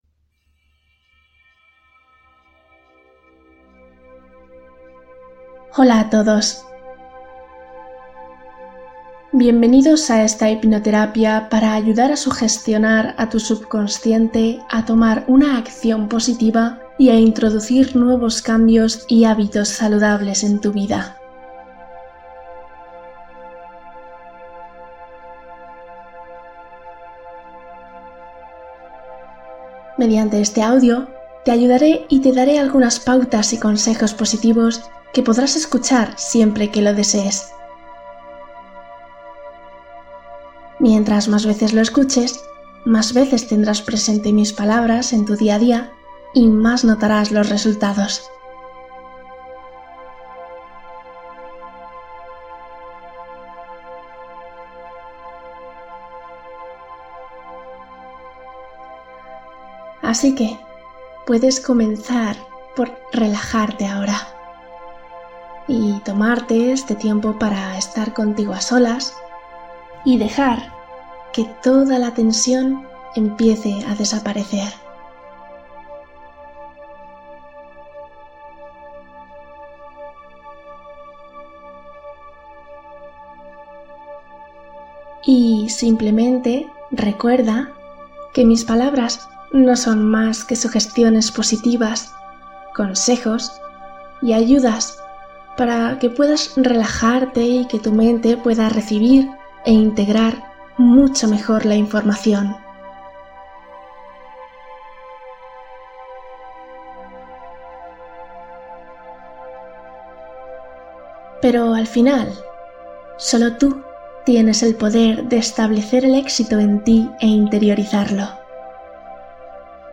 Hipnosis orientada a cultivar una relación amable con el cuerpo